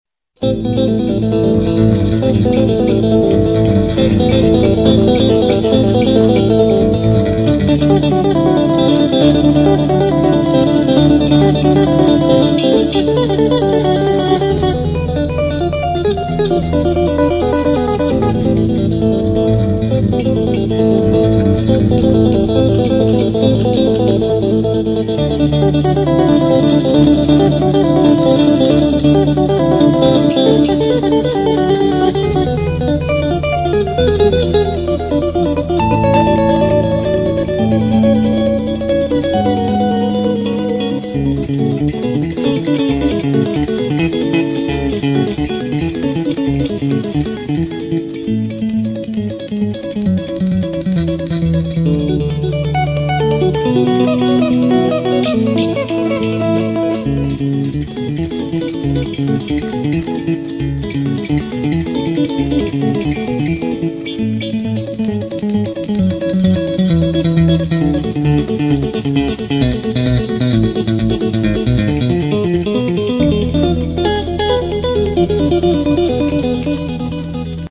ФЬЮЖН